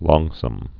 (lôngsəm, lŏng-)